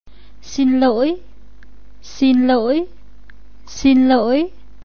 xin loi  e “loi”就是nga調)對台灣人來講khah有困難發音，其他long無啥大問題。越南nga調是，高升調、soa-lai雄雄 lak落來、路尾koh雄雄chheng3起去，淡薄仔像“~”e形。